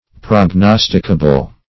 Search Result for " prognosticable" : The Collaborative International Dictionary of English v.0.48: Prognosticable \Prog*nos"tic*a*ble\, a. Capable of being prognosticated or foretold.